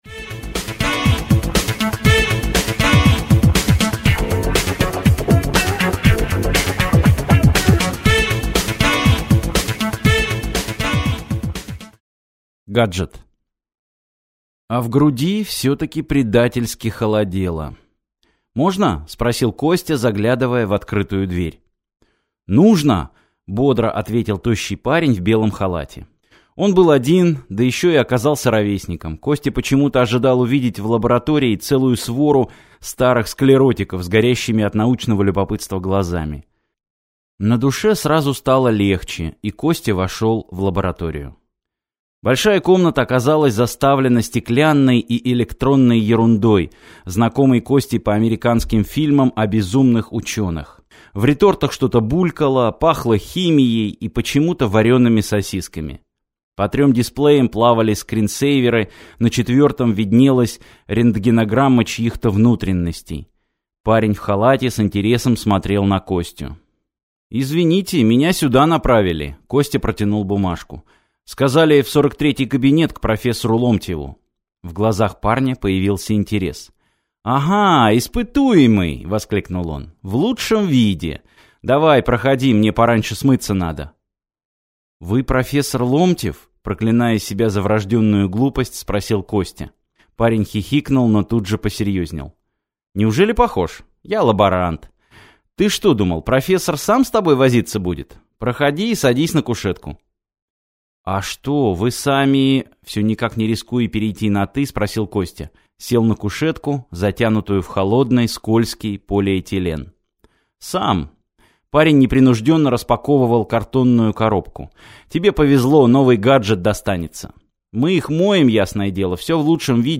Аудиокнига Конец легенды (сборник) | Библиотека аудиокниг